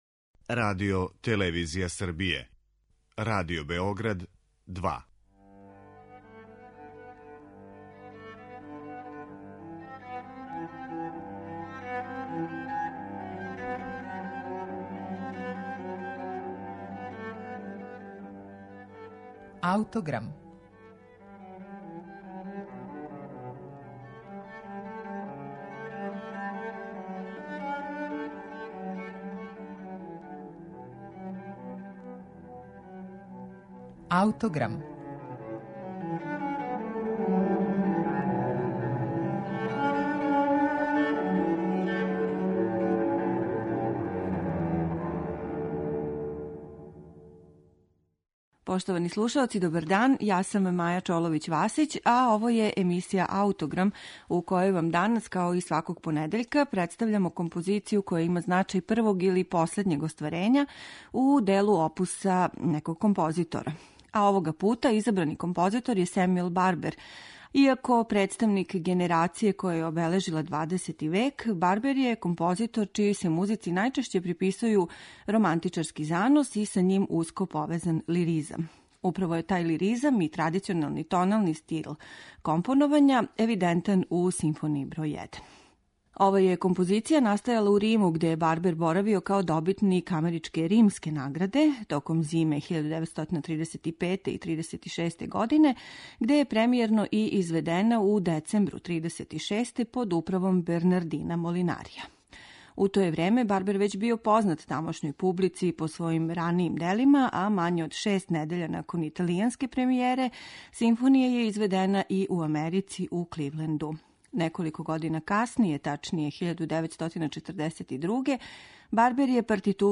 тај лиризам и традиционални тонални стил компоновања